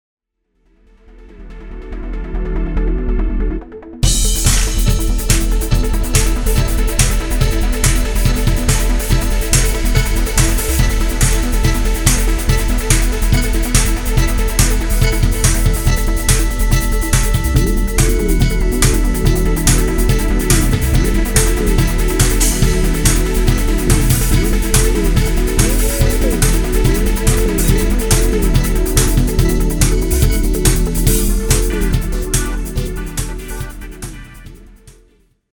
Drum tracking recorded examples-
(Trance) (Dance) (Crushed Big Drums)